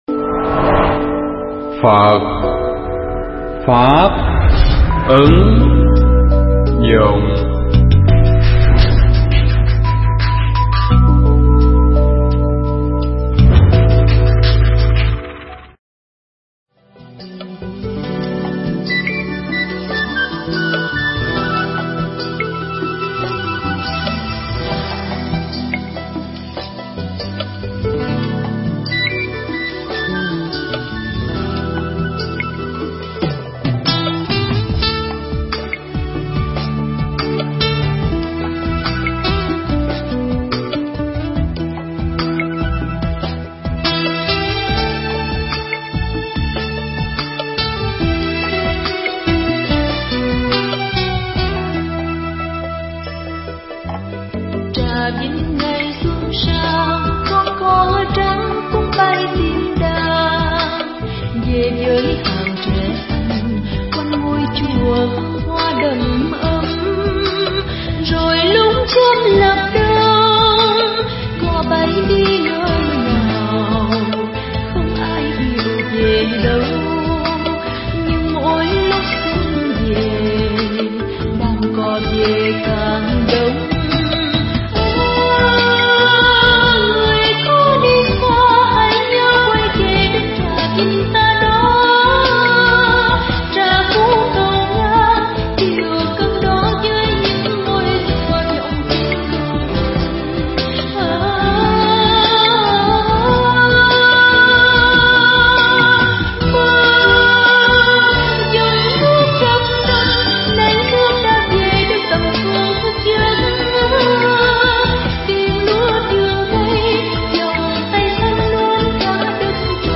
Pháp âm Chỉ Có Một Con Đường